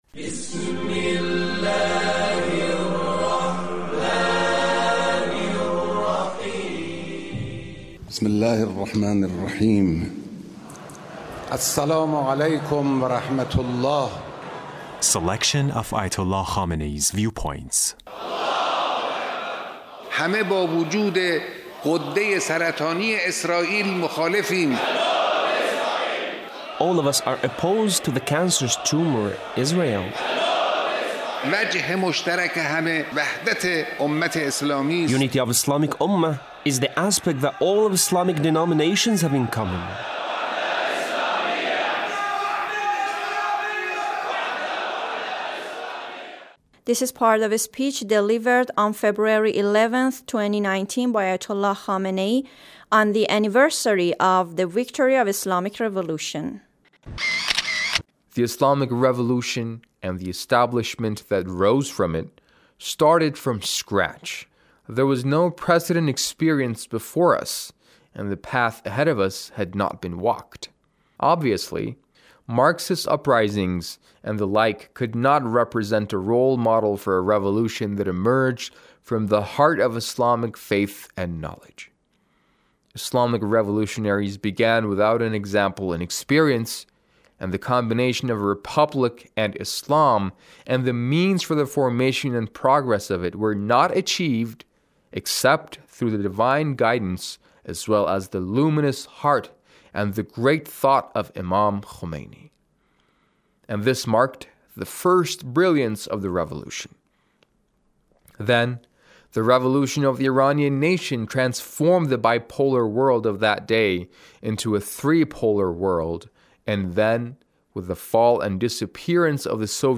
Leader's Speech (13)